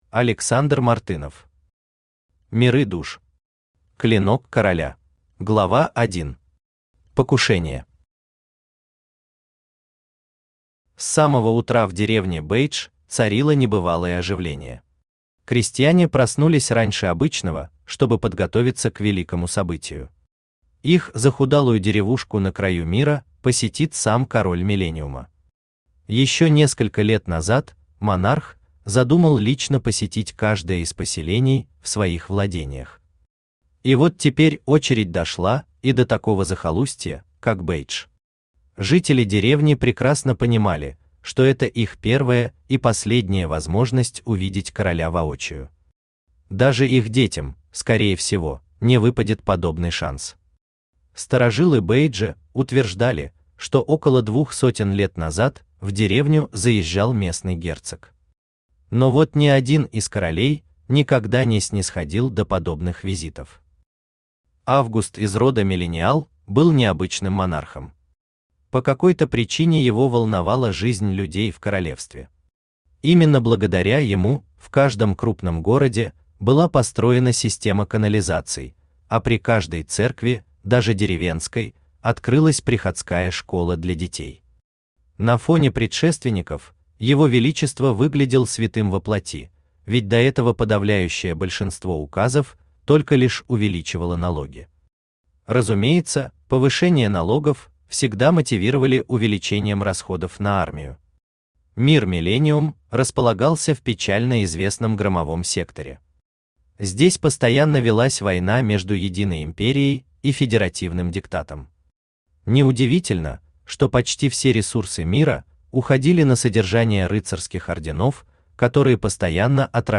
Клинок короля Автор Александр Мартынов Читает аудиокнигу Авточтец ЛитРес.